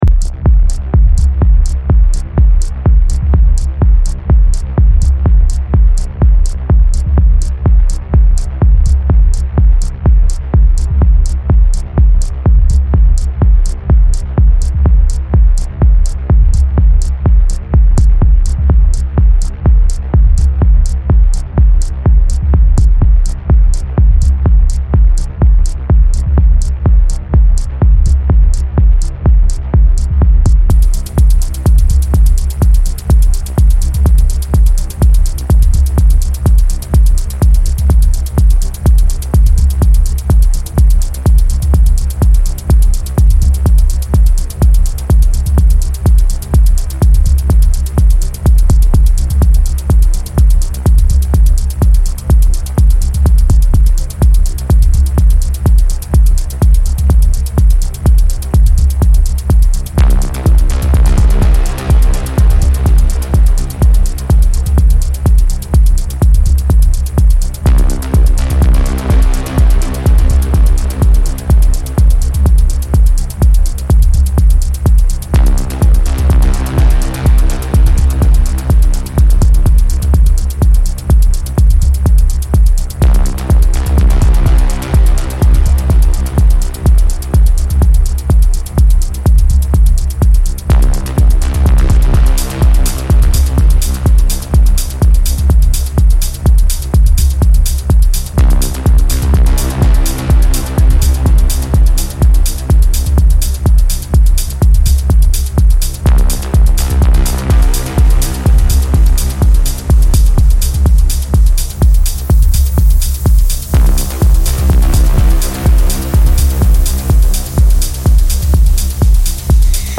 heavily inspired by cosmic and futuristic soundscapes.
progressive to melodic techno